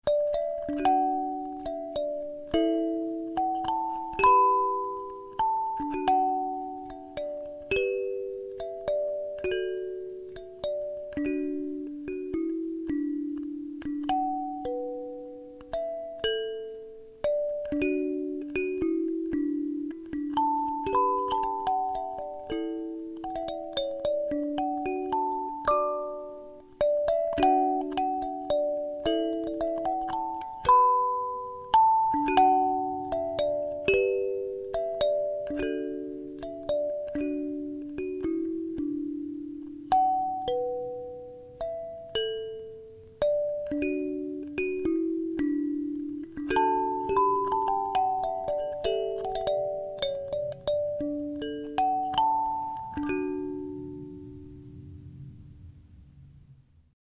A karimba tuned to D major, it is easy to play Celtic music on this instrument
This board-mounted karimba is set up and tuned to play Celtic music in the key of D major.
Unlike other karimbas such as African-tuned or SaReGaMa styles, this instrument does not come with buzzers.
CelticDKarimba.mp3